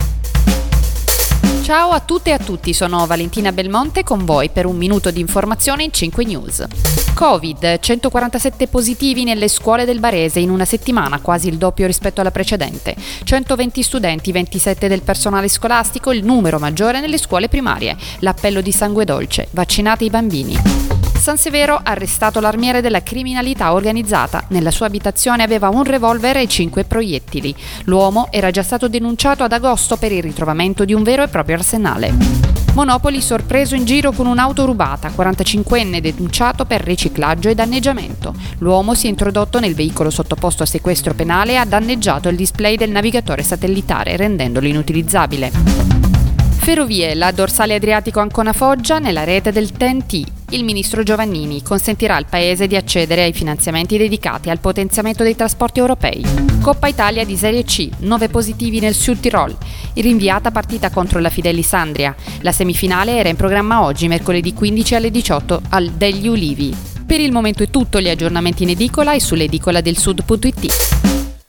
Giornale radio